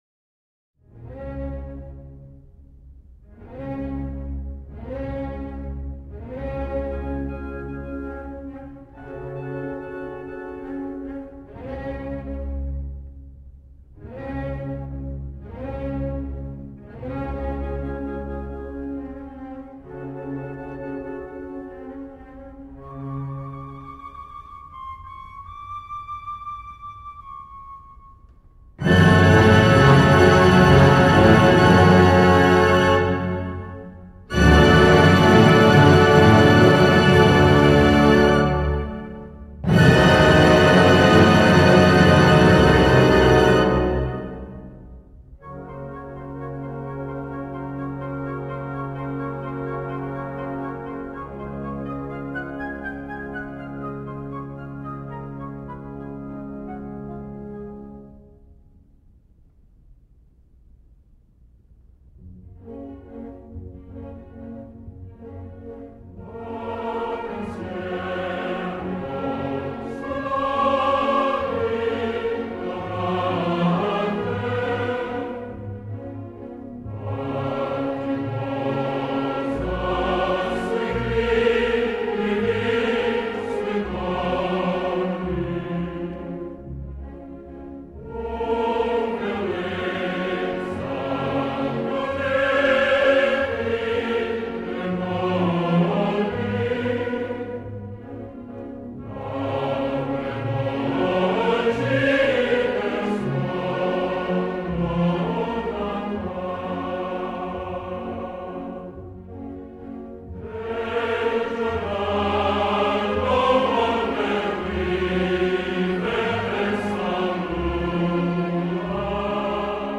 Un extrait d’opéra par jour !
Cette fois-ci, ils y a de nombreux chanteurs ou choristes.